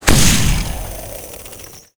iceshard_impact.wav